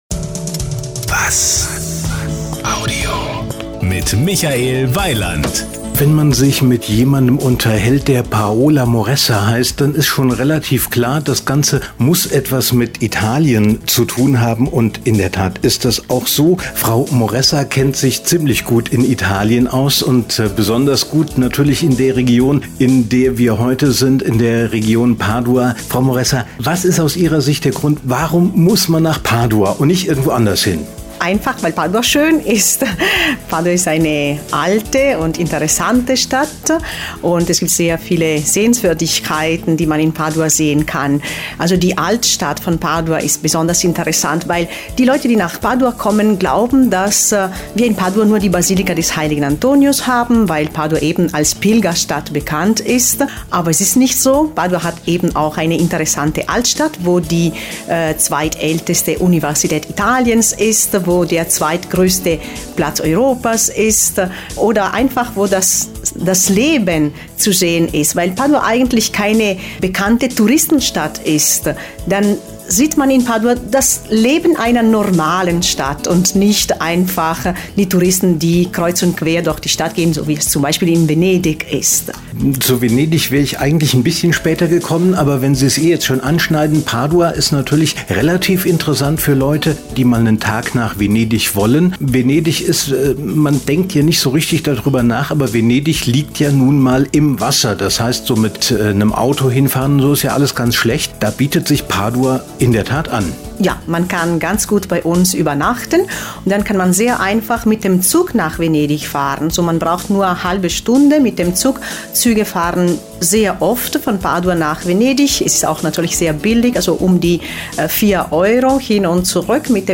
Das komplette Interview hat eine Länge von ca 32 min.